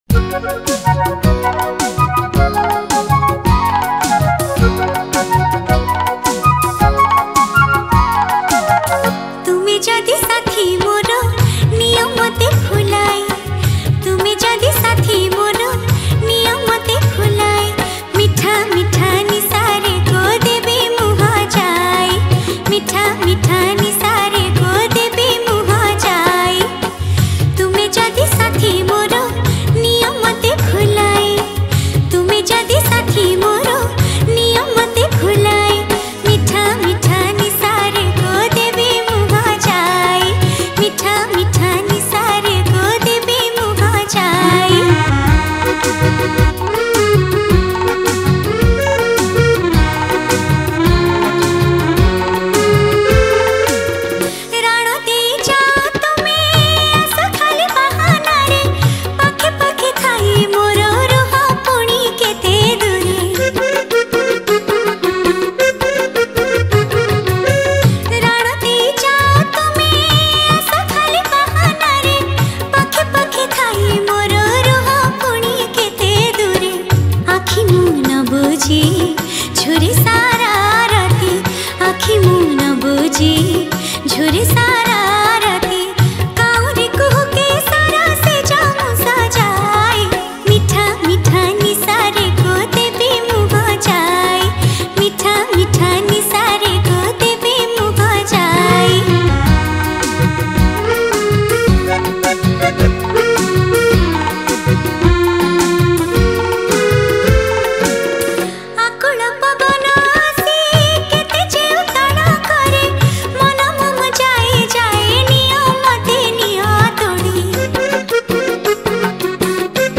Old Romantic Song